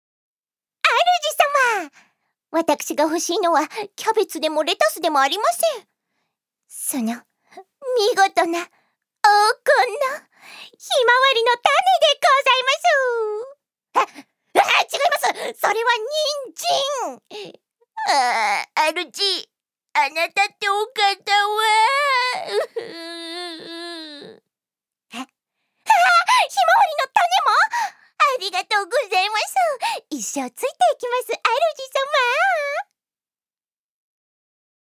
女性タレント
セリフ６